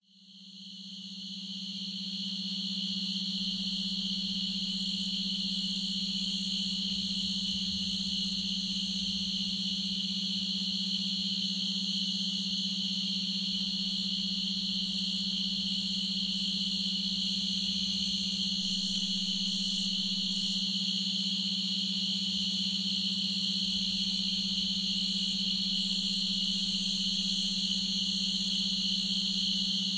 Beautiful nature scenes for relaxing.